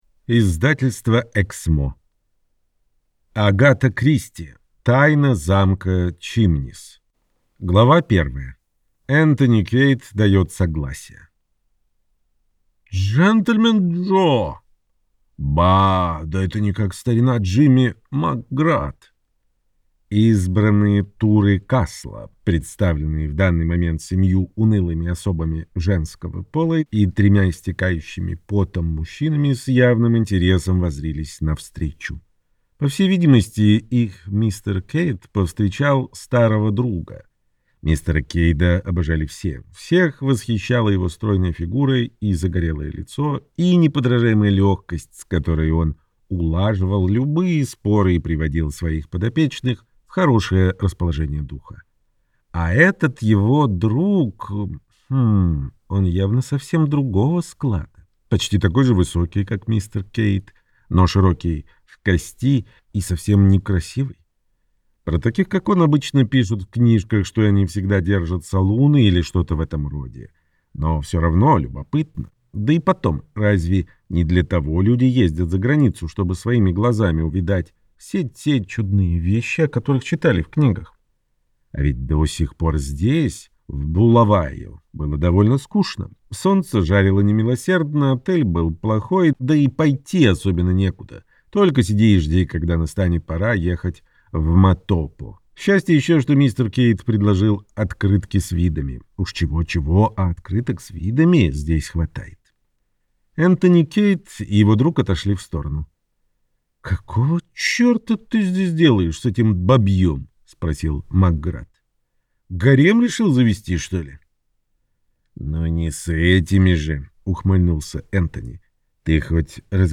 Аудиокнига Тайна замка Чимниз - купить, скачать и слушать онлайн | КнигоПоиск